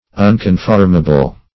Unconformable \Un`con*form"a*ble\, a.